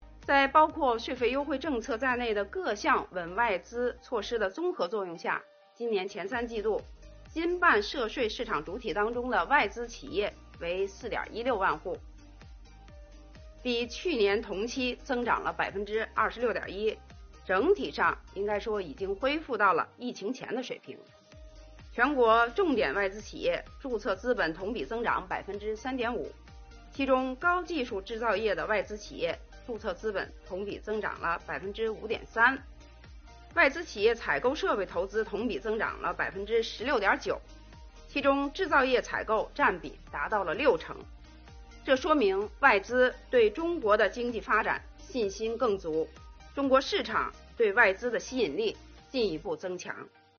近日，国务院新闻办公室举行国务院政策例行吹风会，国家税务总局相关负责人介绍制造业中小微企业缓税政策等有关情况，并答记者问。